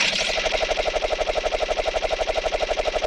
chairLoop.wav